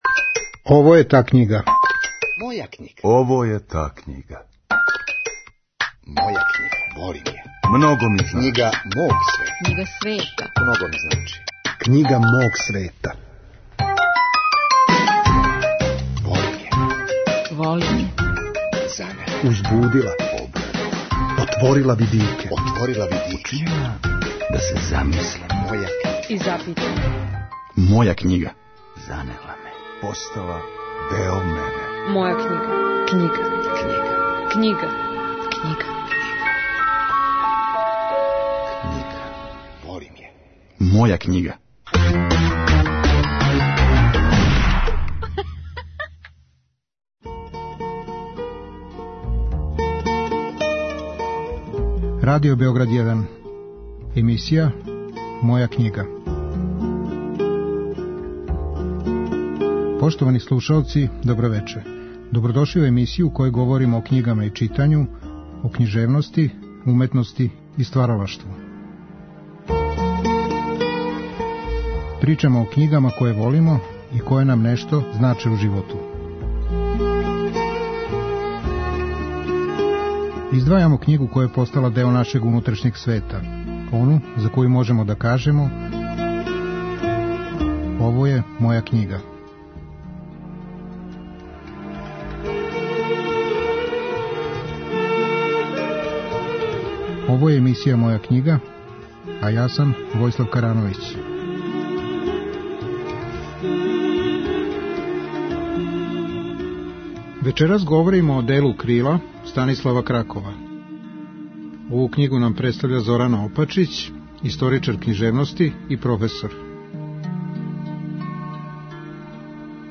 историчар књижевности и професор.